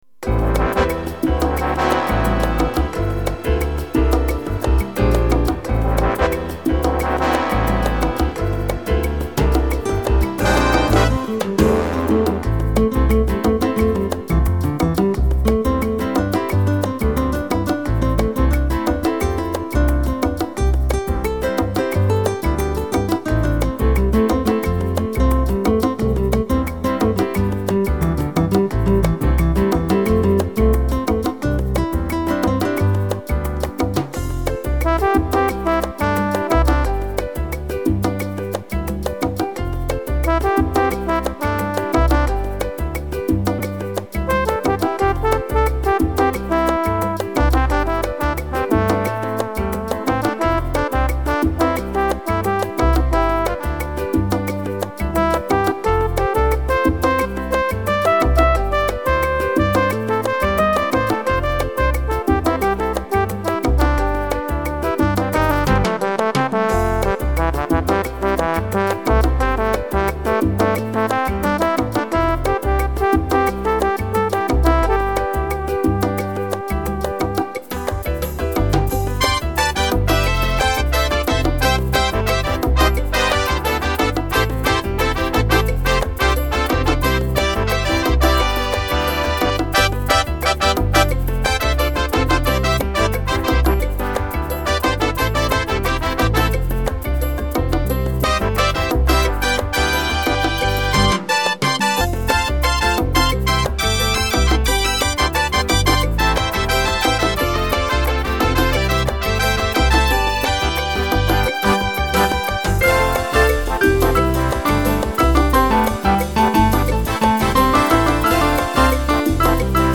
עוד ▼ שמע אימפרוביזציה מאת אנונימי פורסם בתאריך כ"ט בסיוון תשס"ט, 21.6.2009 במסגרת לימודי הפסנתר אני לומד גם 'אימפרוביזציה' - אלתור במקרה הזה - סולם בלוז בקצב סלסה..
וההקלטה עצמה לא ממש איכותית. יש מדי פעם בעיות דרימה בנגינה.